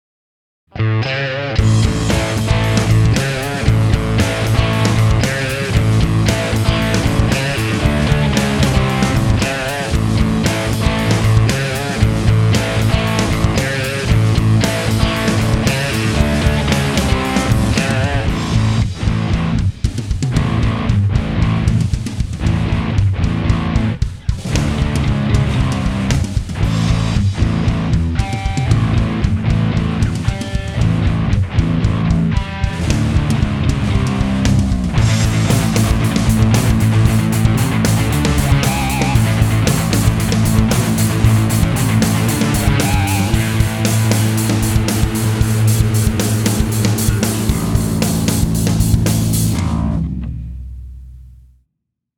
Digitech Hot Head DistortionОчень хороший и вкусный дисторшн. Записал его напрямую в компьютер с выхода спикерсимулятора (в педали есть такой), как он звучит в комбик, думаю несложно представить (заодно и видео прилагается).
Вообще, у меня к этой педали никаких нареканий, разве что, компрессия звука могла бы быть поменьше (не хватает динамики), и из-за чересчур сильной компрессии поведение регуляторов тембра тоже весьма забавно.
А в целом у Digitech Hot Head Distortion отличный современный звук.
digitech_hot_head_test.mp3